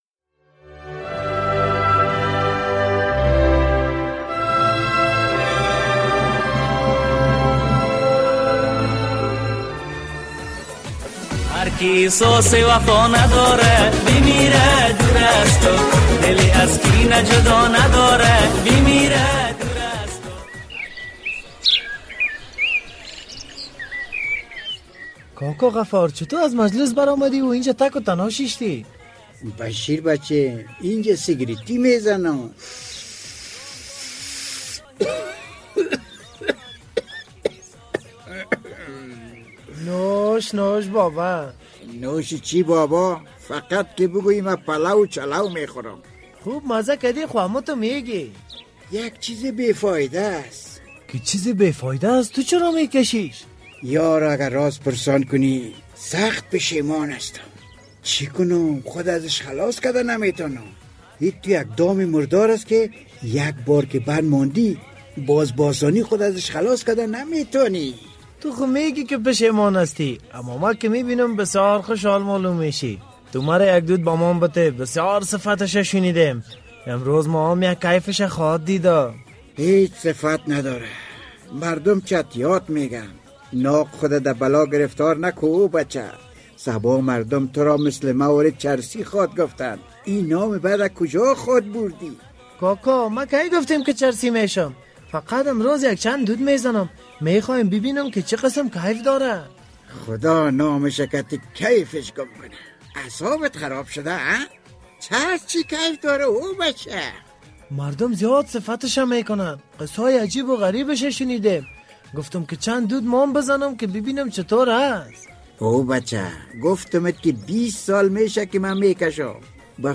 درامه کاروان زهر
یک روز همرا با دوستانش به تفریح می‌رود، خوشی ها، صدای موسیقی، کف زدن‌ها و خنده سرا سر باغ را فرا می‌گیرد.